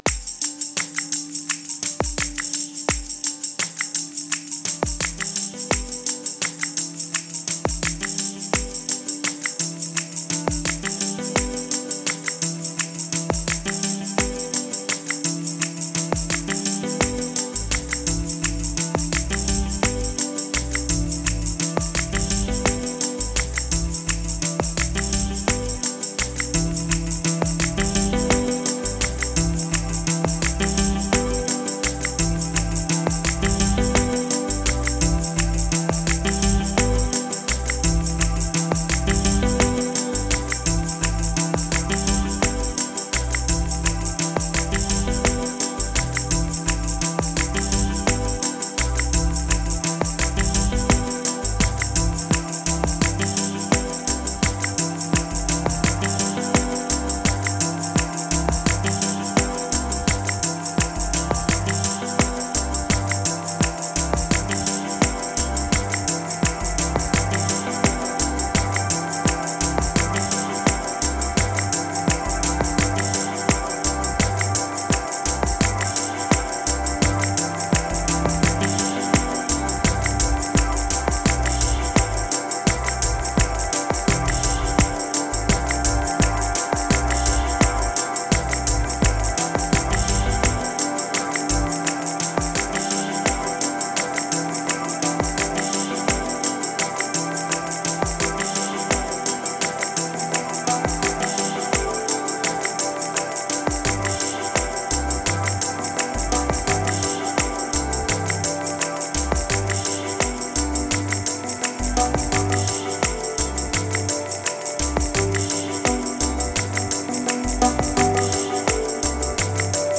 Moods Demo Single Memories Afar Abyss Clone Resume Ambient